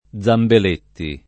[ +z ambel % tti ]